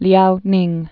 (lyounĭng)